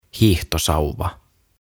Tuhat sanaa suomeksi - Ääntämisohjeet - Sivu 4